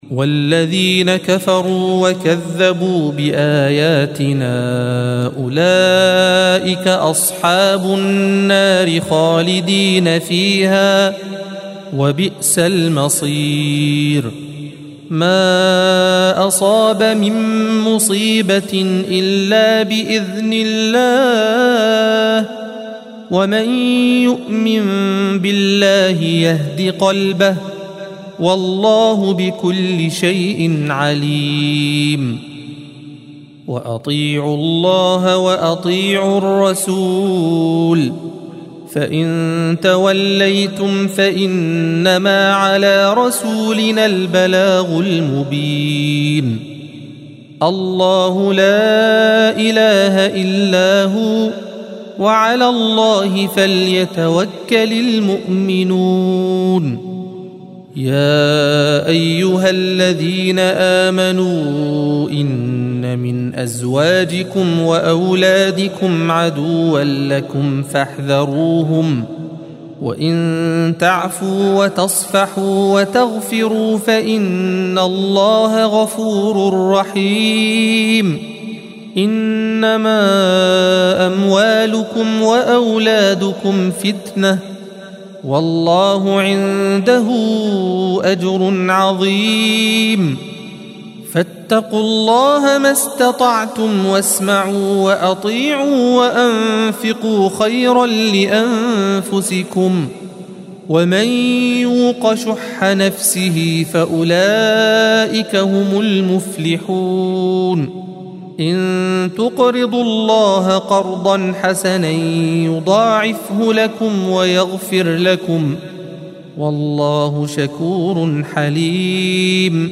الصفحة 557 - القارئ